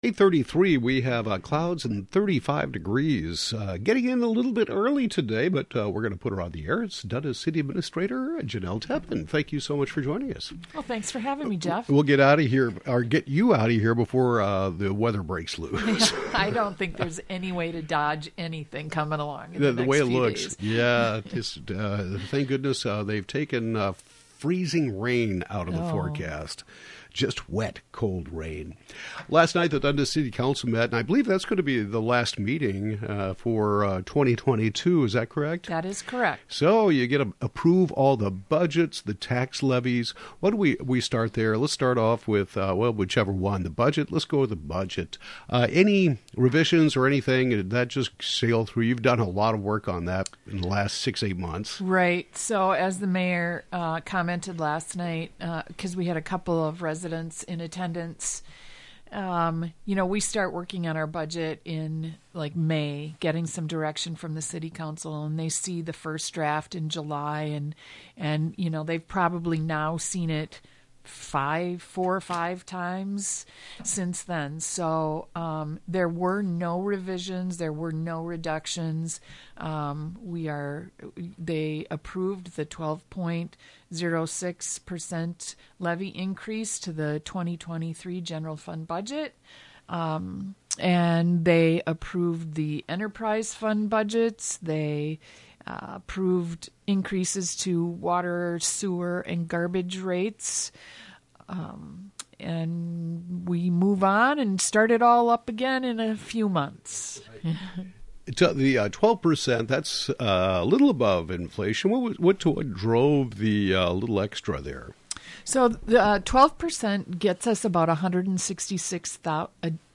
Dundas City Administrator Jenelle Teppen talks about the December 12 City Council meeting including approval of the levy increase and the budget. A tentative labor agreement with the police officers’ bargaining unit was also approved.